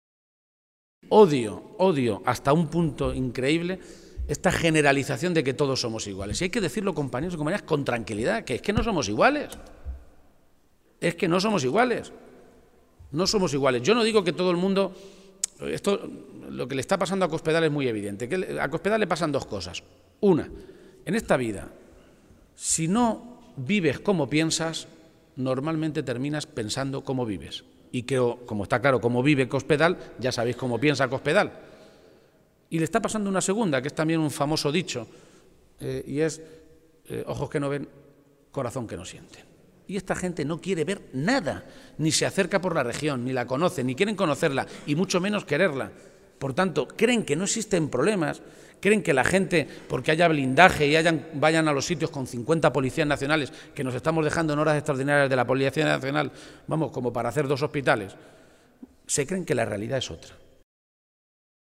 Momento de la intervención de García-Page
El secretario general de los socialistas de Castilla-La Mancha, Emiliano García-Page, ha mantenido este mediodía un encuentro con militantes y simpatizantes del PSOE de la comarca de La Sagra toledana en la localidad de Illescas, y ha aprovechado su intervención para hacer referencia a algunos de los asuntos que están marcando la actualidad en las últimas semanas.